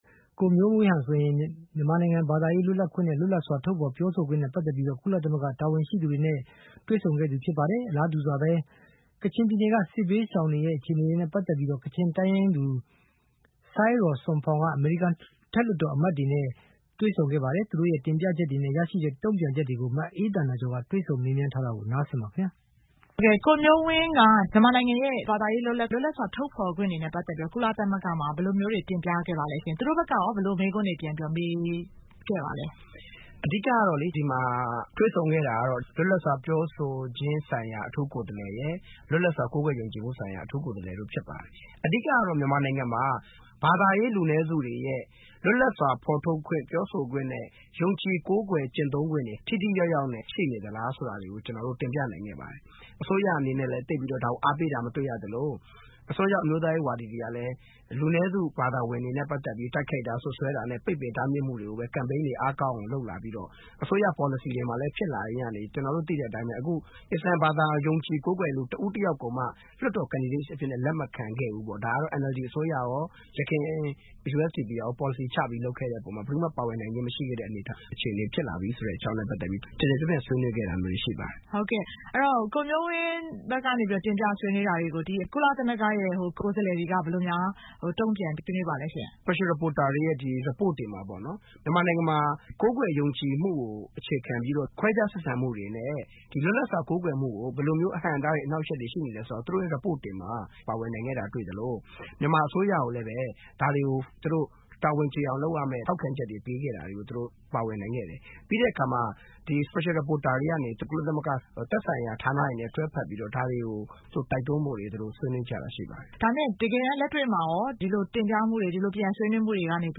တွေ့ဆုံမေးမြန်းထားတာ ဖြစ်ပါတယ်။